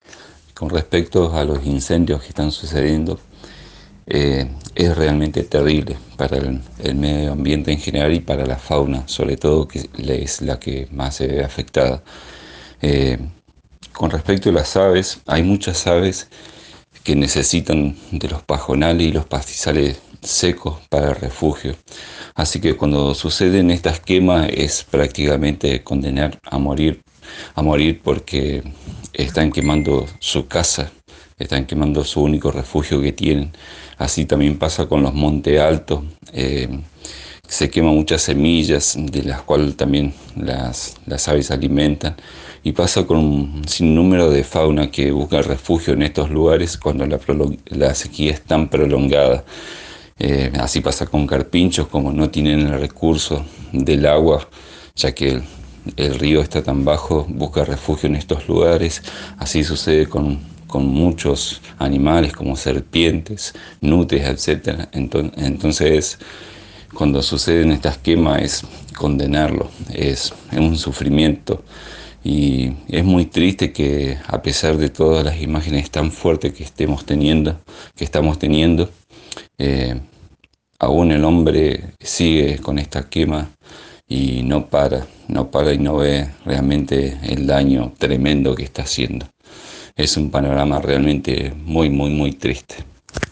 Agencia eu! consultó a un especialista en Aves, conocedor del comportamiento de estos animales, para conocer los detalles en relación a lo que sucede con esta realidad.